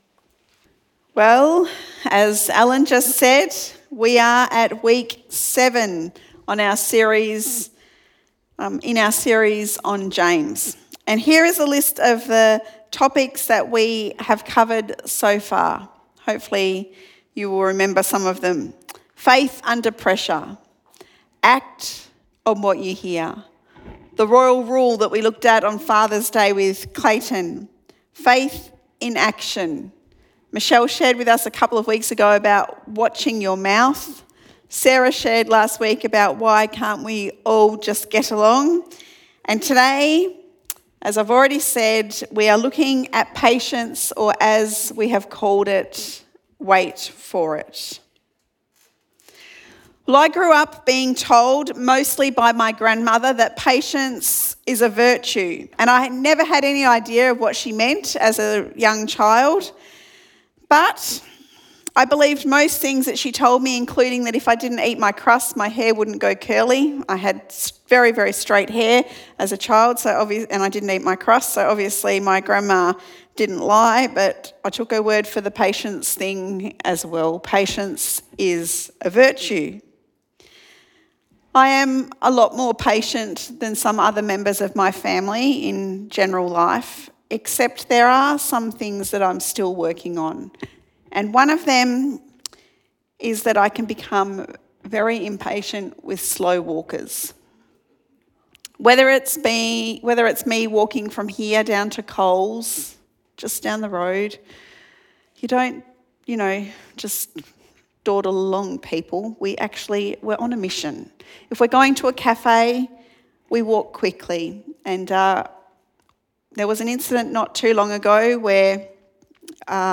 Sermon Podcasts James